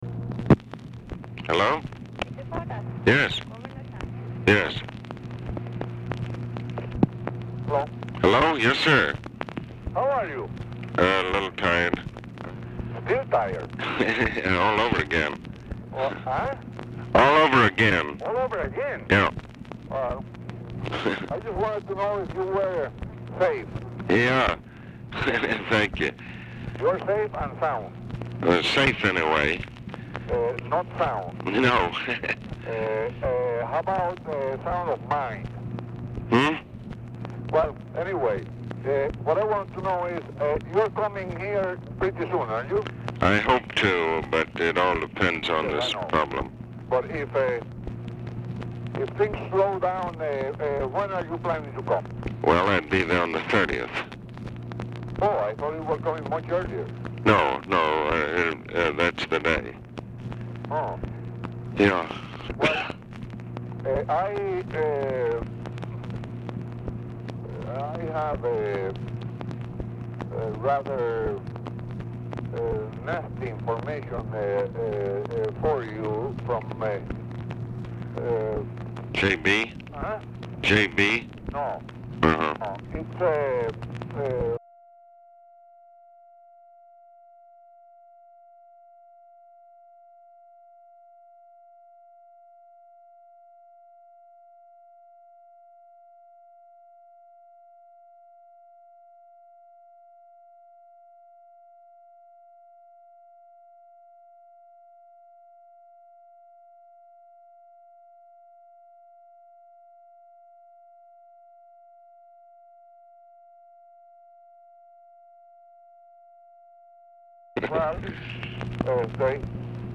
Telephone conversation # 7750, sound recording, ABE FORTAS and ROBERTO SANCHEZ-VILELLA, 5/18/1965, 11:25AM | Discover LBJ
Format Dictation belt
Specific Item Type Telephone conversation